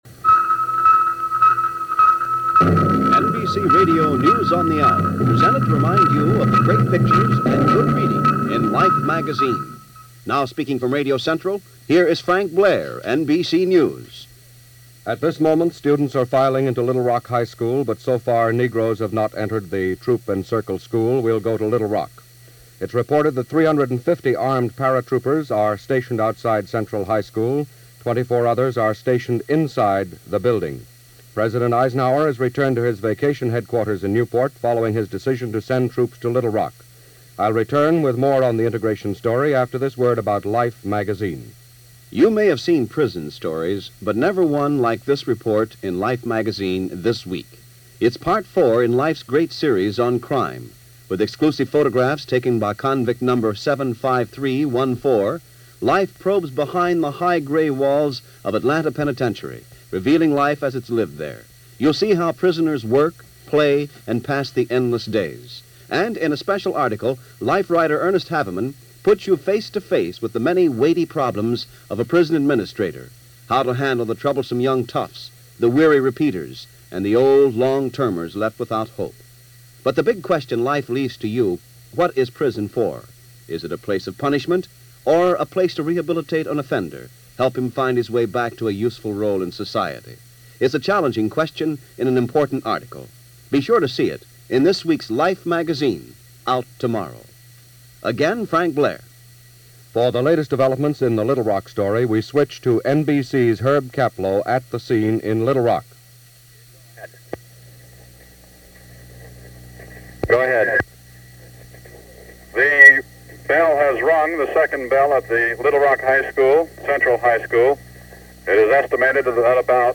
A Place Called Little Rock - September 25, 1957 - news reports on the first full day of school at Central High and Integration.